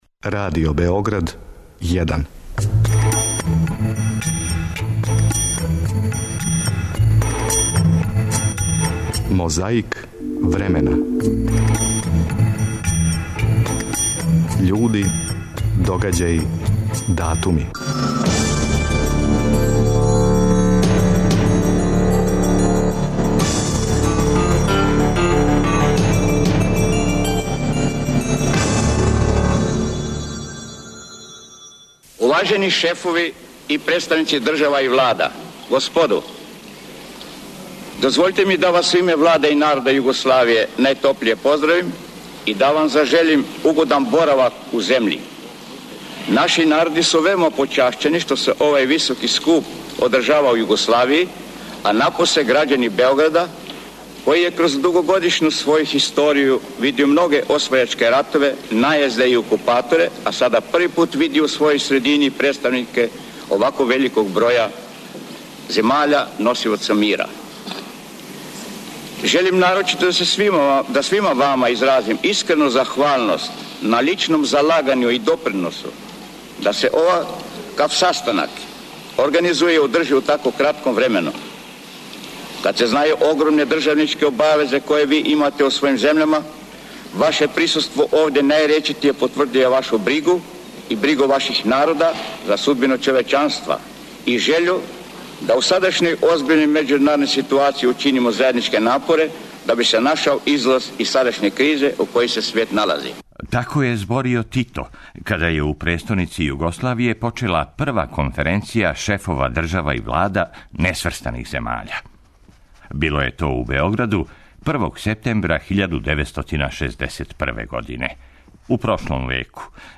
Захваљујући тонском архиву Радио Београда који чува снимак с краја прошлог века, чућемо и Ратка Младића.